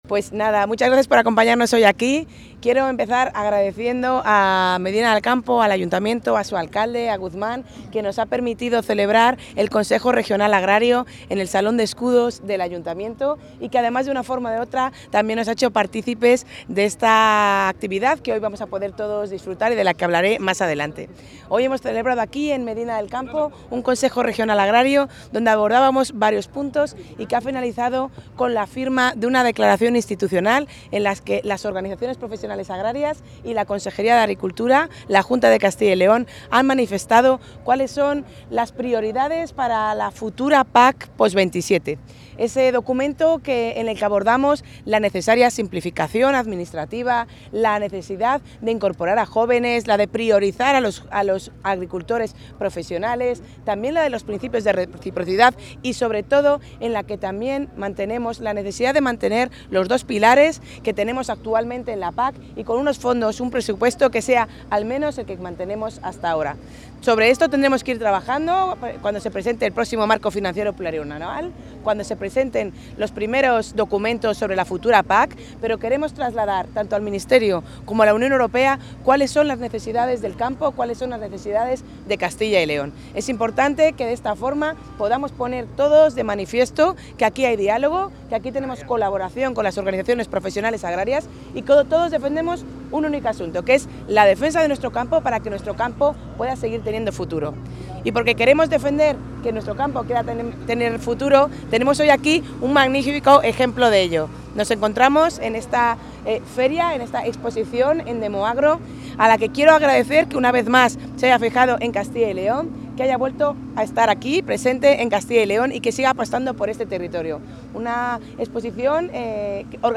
El Consejo Agrario de Castilla y León, celebrado esta mañana en el Salón de Escudos del Ayuntamiento de Medina del Campo, ha...
Intervención de la consejera.